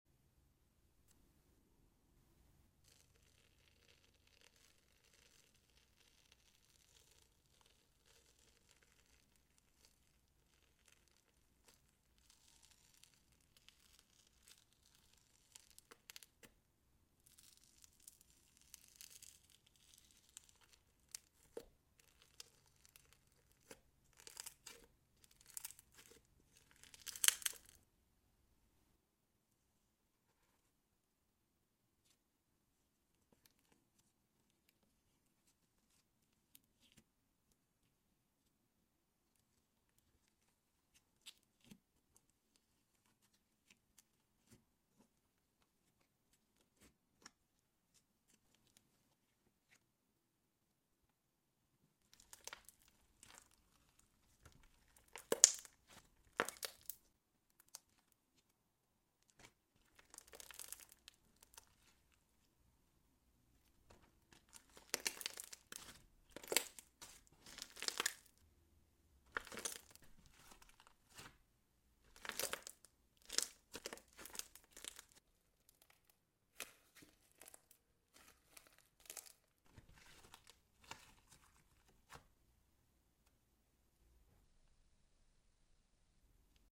Satisfying slime tingles ASMR | sound effects free download